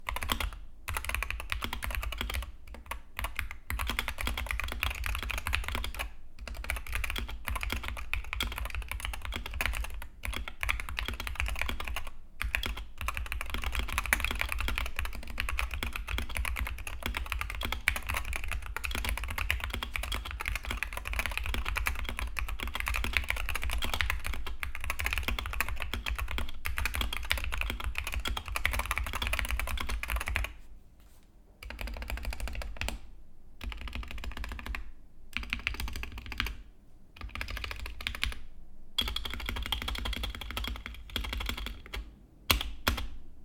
Każdemu wciśnięciu towarzyszy bowiem nietypowy pogłos, jakby szelest.
Z daleka przypomina on jednak bardziej grzechotkę, a to niestety nie brzmi zbyt przyjemnie dla uszu.
Tak brzmi klawiatura Wooting 60HE.
Jak widzicie, a raczej słyszycie, Wooting 60HE domyślnie brzmi niezbyt przyjemnie, ale kilka prostych i niedrogich modyfikacji wystarczy, żeby doprowadzić tę klawiaturę do wręcz idealnego poziomu.
recenzja-Wooting-60HE-soundtest.mp3